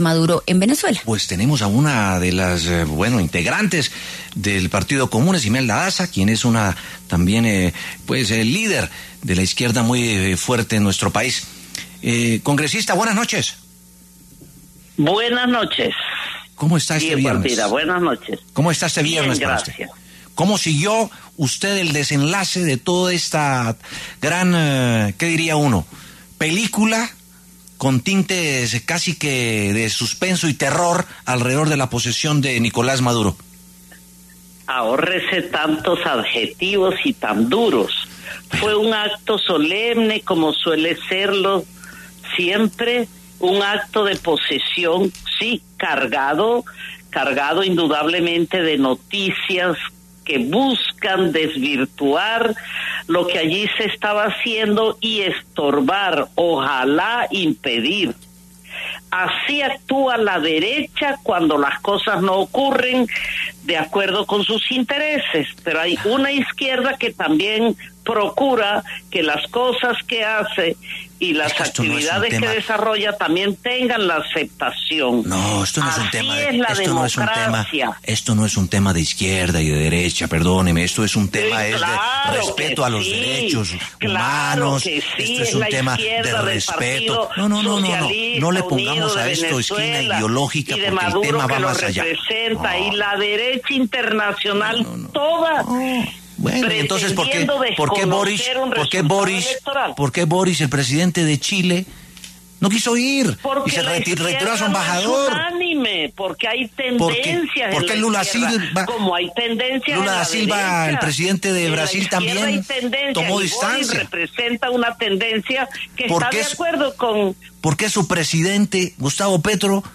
La senadora del Partido Comunes, Imelda Daza, conversó en W Sin Carreta sobre el acto de posesión de Nicolás Maduro en Venezuela y las múltiples críticas en contra.